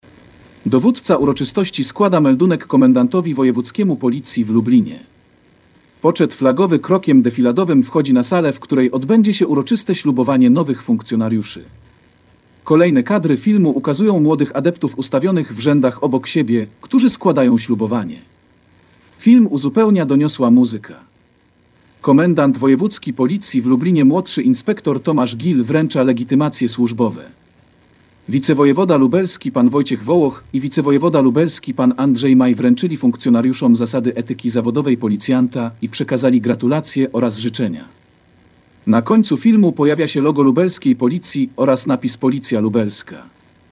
Nagranie audio Audiodeskrypcja filmu stu nowych policjantów w Lubelskiej Policji